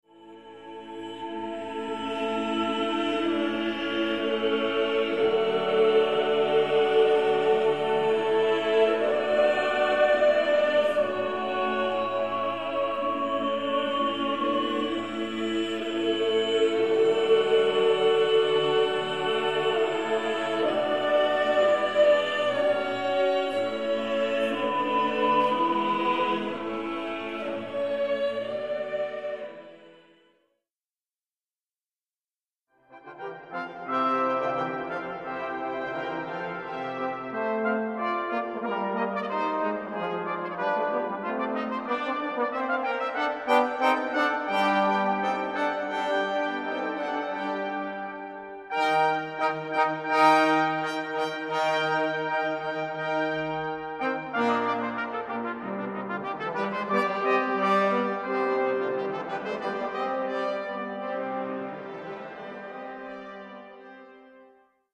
L'ascolto propone due frammenti musicali appartenenti, rispettivamente, alla scuola romana e alla scuola veneziana.